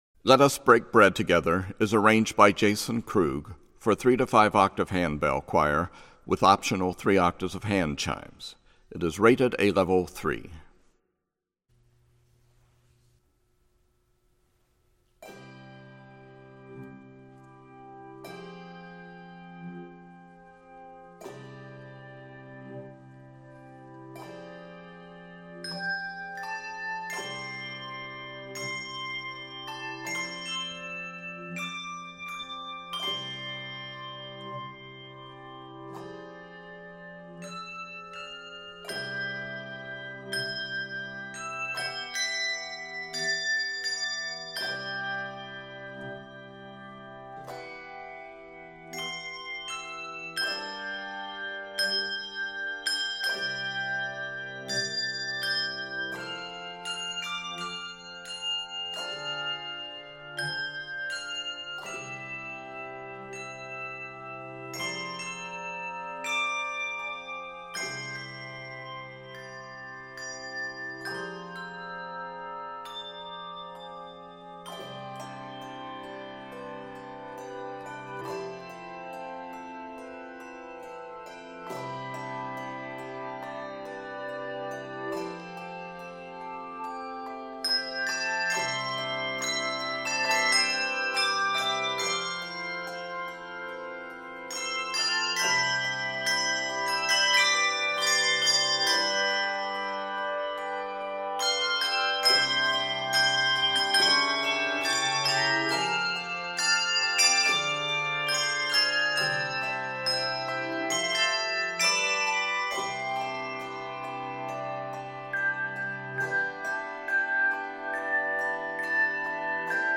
Keys of C Major.
Octaves: 3-5